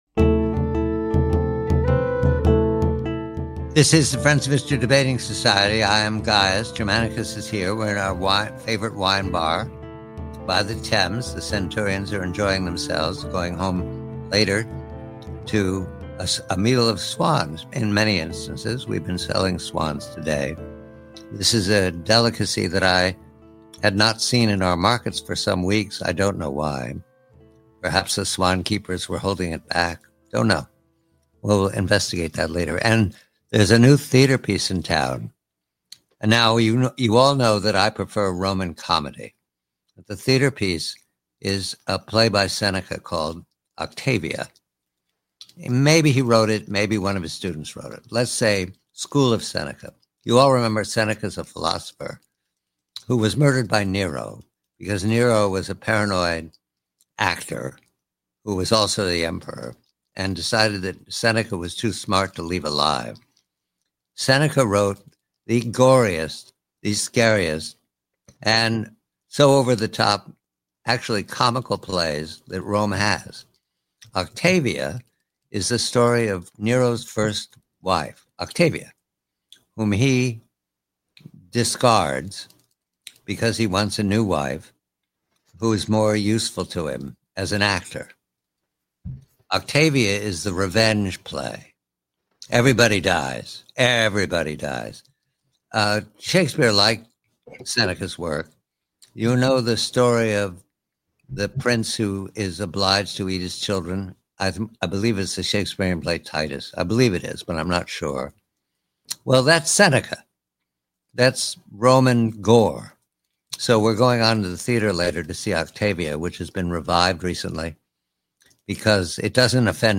The conversation focuses on the nature of political revenge, prompted by the revival of Seneca's bloody revenge play Octavia .